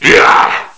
assets/psp/nzportable/nzp/sounds/zombie/a1.wav at 25ec730b1a9f8d1e29d9178d7967ab4faa8ab251
PSP/CTR: Also make weapon and zombie sounds 8bit